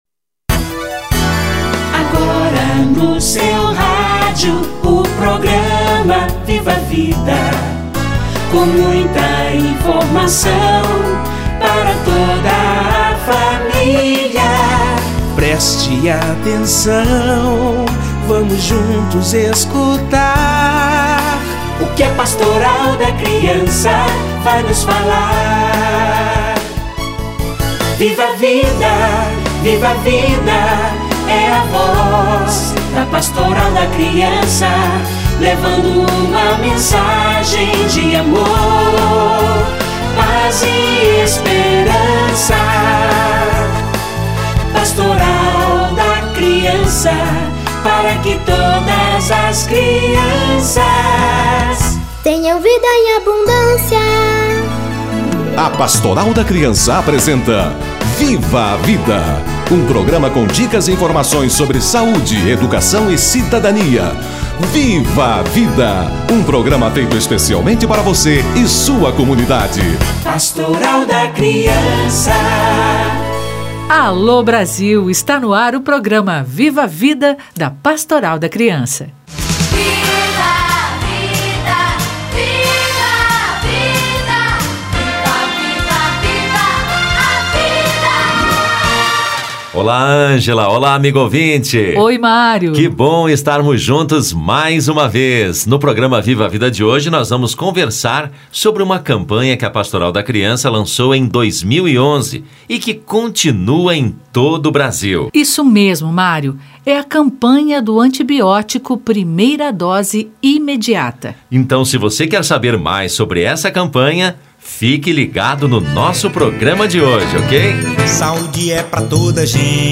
Antibiótico: primeira dose imediata - Entrevista